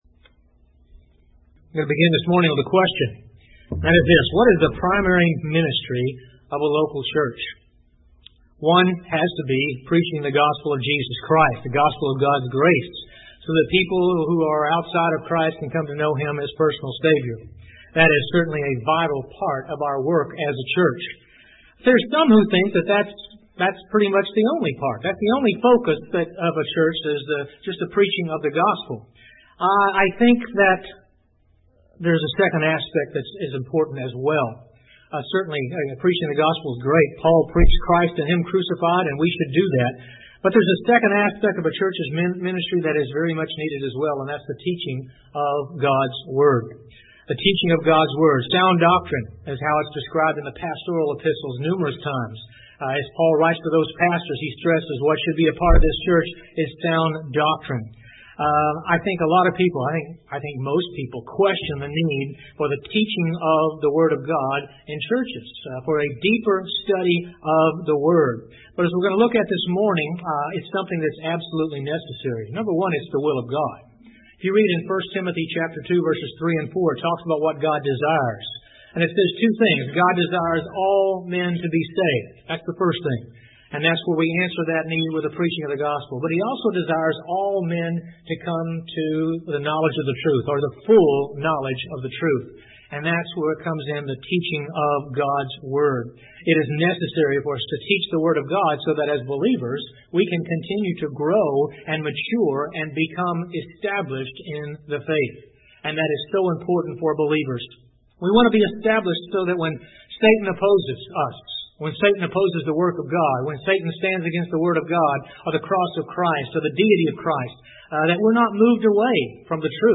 Listen to Bible sermons from our Sunday Morning Worship Services (all Bible Study Sermons are in MP3 format).
The preaching is expository in approach (examining Bible passages in context), even when addressing topical areas (i.e. salvation, Christian walk, faith, etc.).